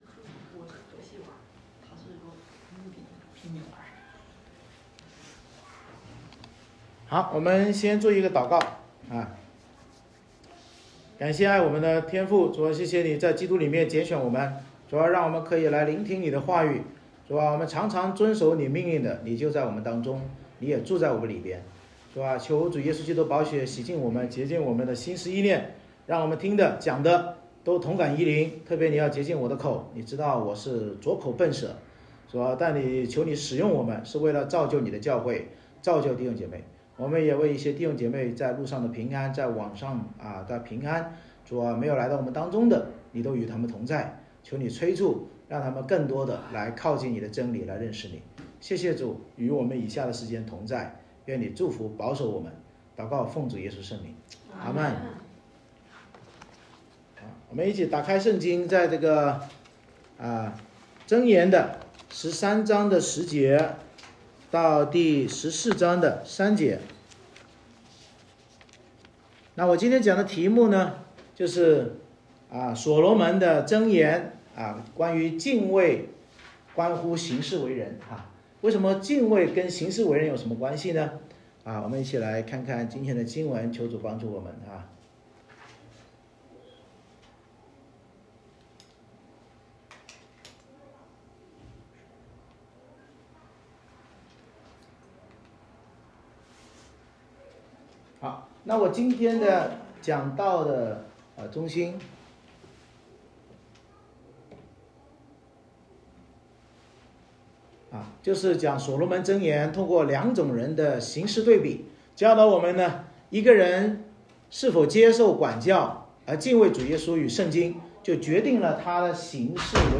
箴言13:11-14:3 Service Type: 主日崇拜 所罗门箴言通过两种人行事对比教导我们：一个人是否接受管教而敬畏主耶稣与圣经，决定了他行事为人是正直还是乖僻。